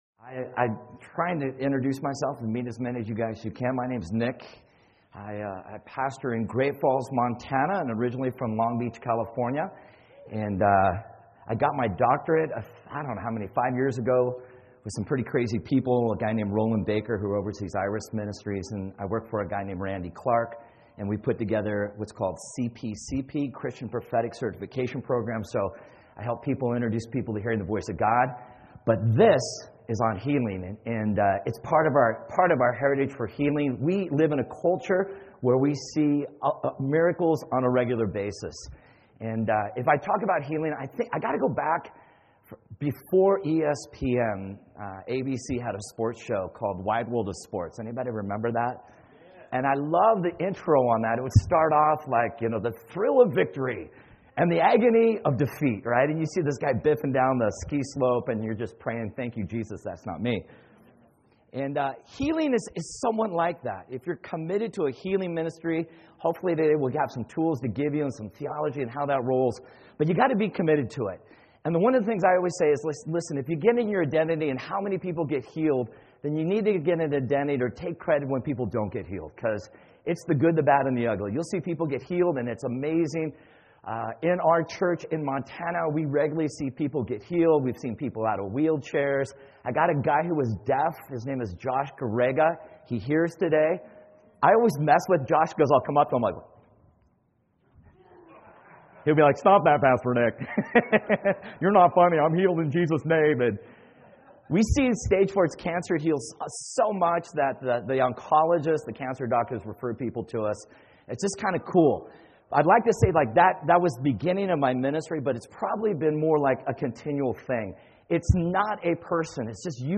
Workshop: The great ‘I Am’ or the great ‘I Was’?